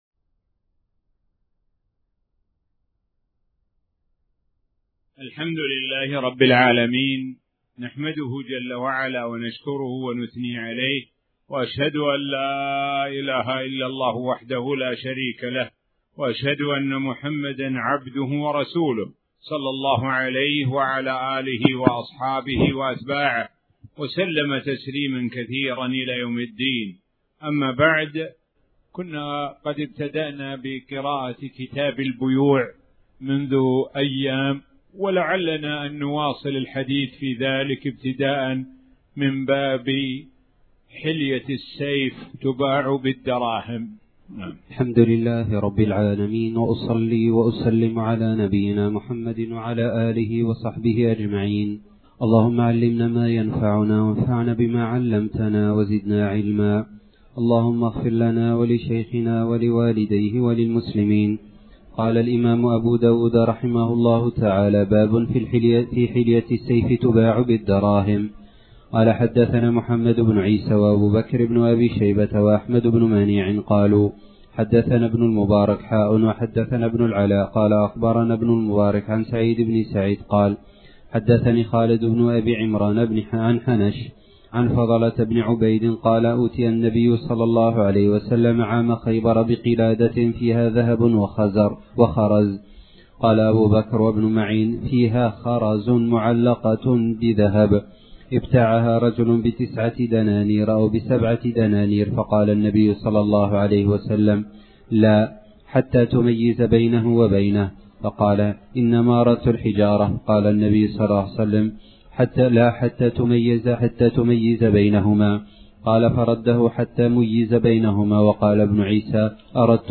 تاريخ النشر ٤ ذو الحجة ١٤٣٩ هـ المكان: المسجد الحرام الشيخ: معالي الشيخ د. سعد بن ناصر الشثري معالي الشيخ د. سعد بن ناصر الشثري باب حلة السيف تباع بالدراهم